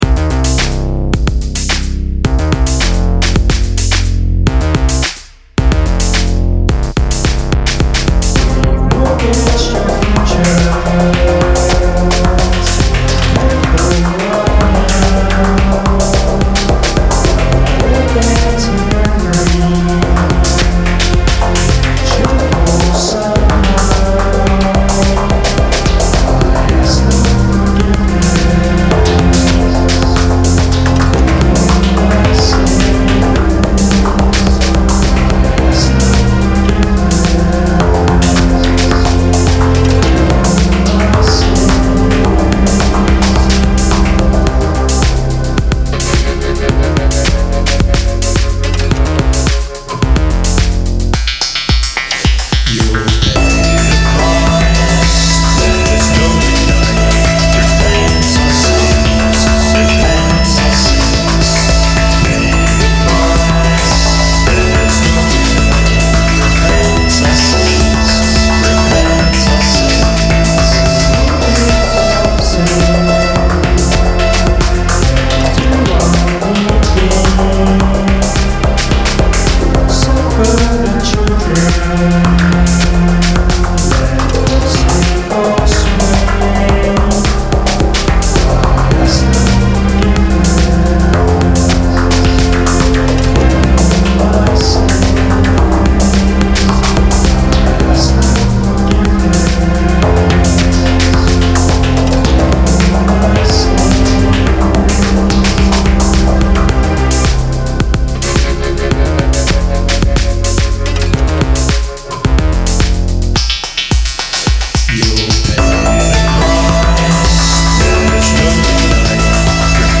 recorded at the garage, corsham